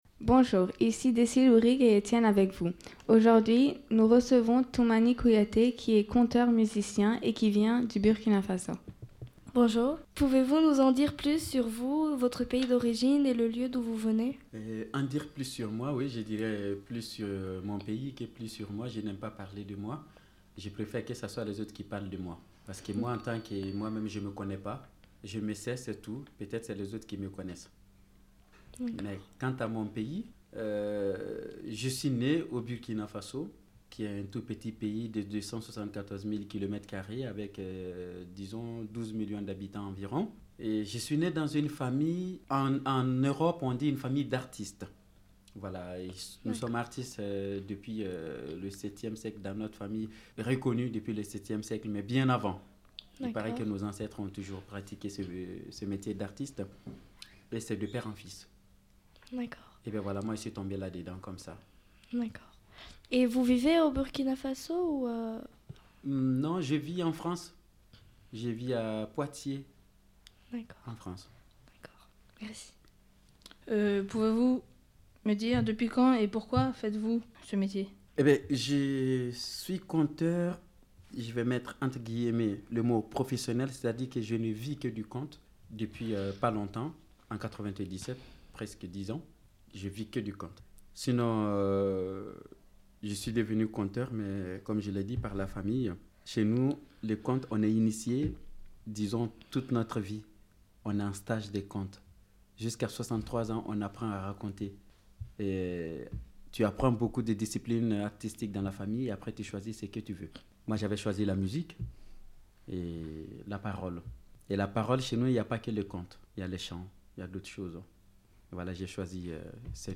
UN CONTEUR VENU DU BURKINA FASO